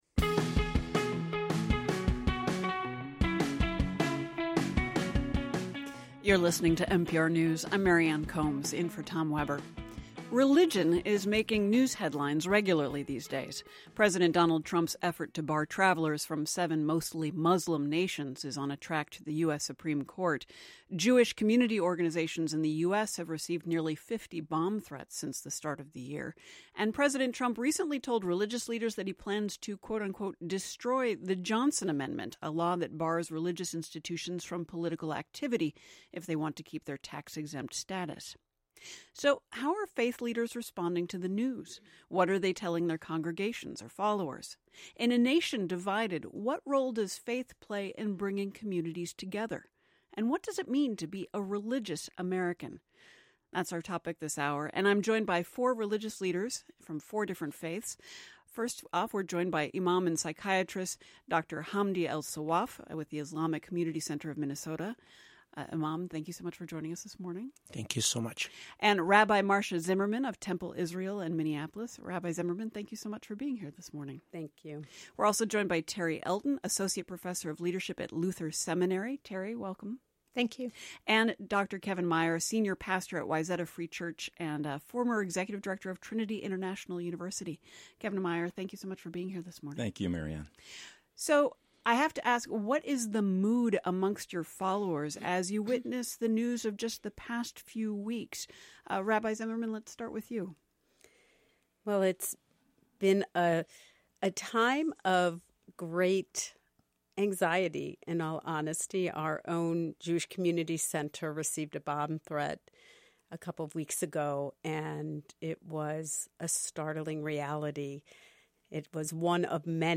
MPR interview